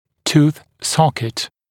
[tuːθ ‘sɔkɪt][ту:с ‘сокит]альвеола зуба, зубная альвеола, зубная ячейка